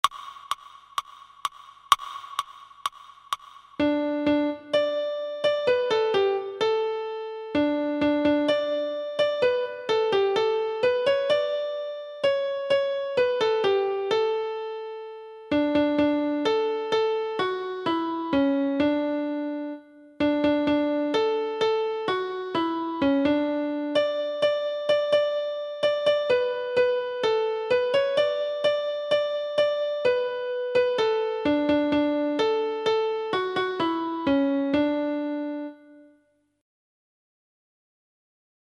Practice - Audition CD